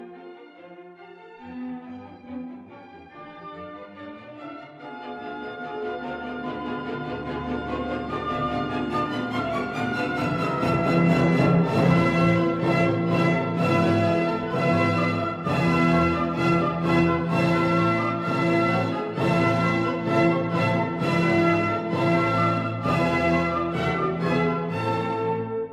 This 25-second clip is from Wiener Philharmoniker, directed by Leonard Bernstein.
I listen to this, and it feels like a vast empire falling apart, pillars crumbling, brave men and women making desperate stands and being overwhelmed.